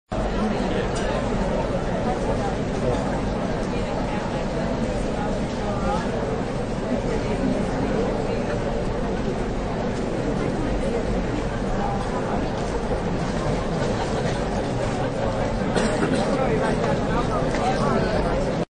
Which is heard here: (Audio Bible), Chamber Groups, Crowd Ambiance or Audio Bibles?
Crowd Ambiance